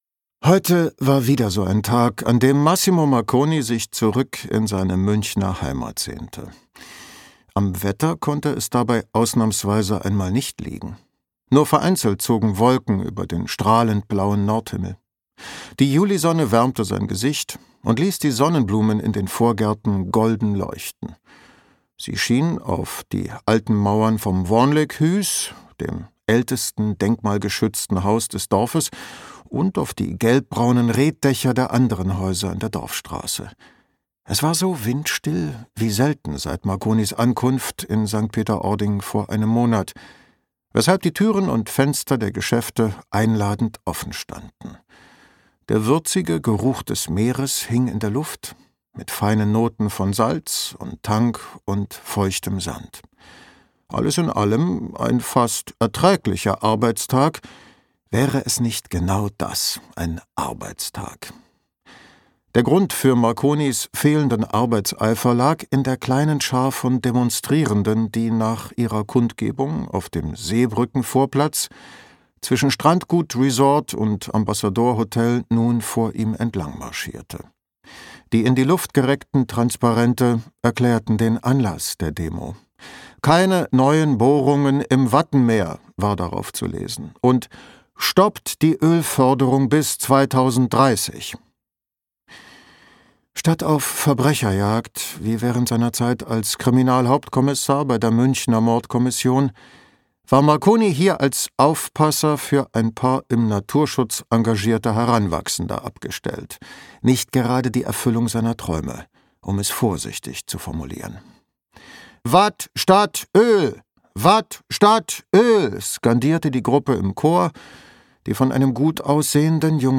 Marconi und der verschwundene Wattschützer - Daniele Palu | argon hörbuch
Gekürzt Autorisierte, d.h. von Autor:innen und / oder Verlagen freigegebene, bearbeitete Fassung.